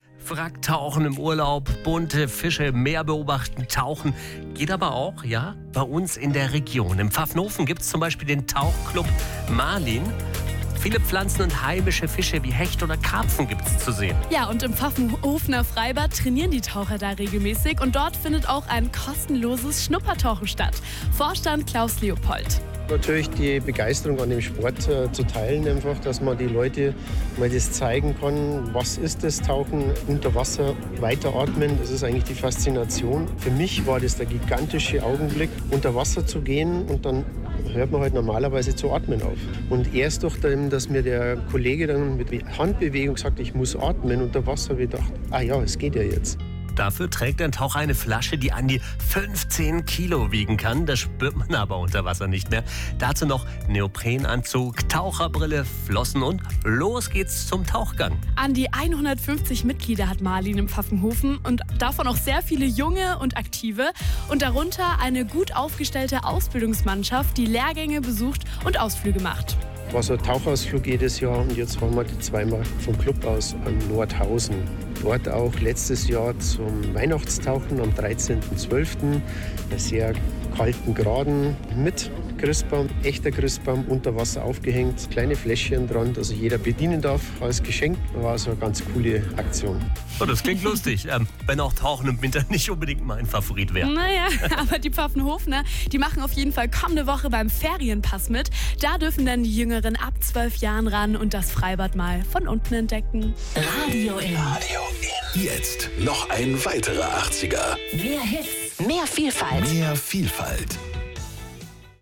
Radio IN hat uns besucht und zwei spannende Interviews aufgenommen.